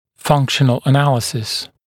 [‘fʌŋkʃənl ə’næləsɪs][‘фанкшэнл э’нэлэсис]функциональный анализ